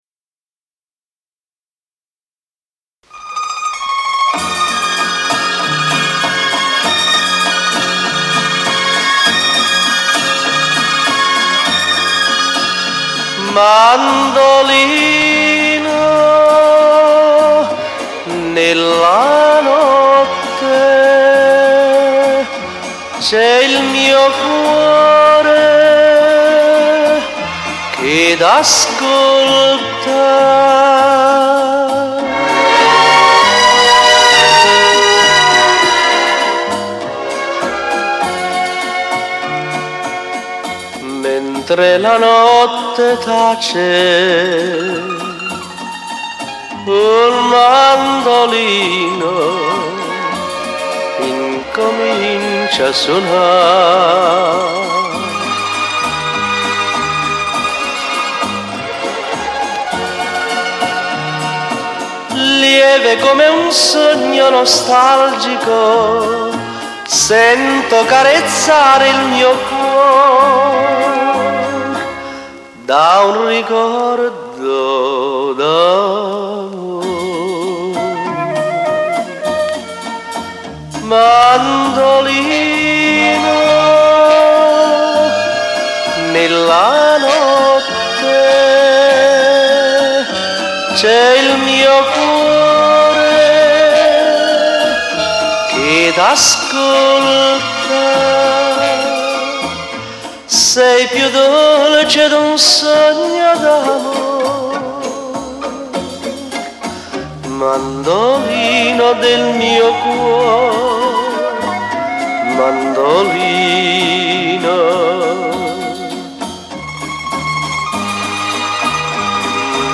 con Orchestra